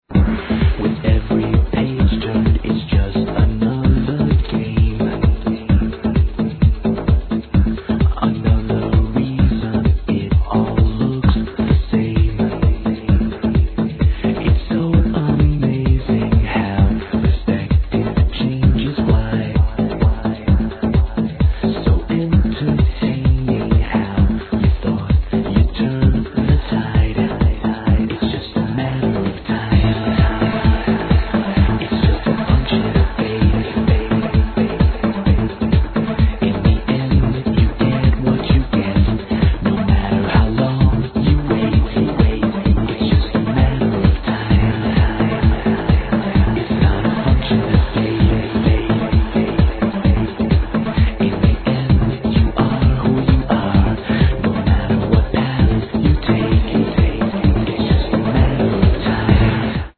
Another vocal prog house track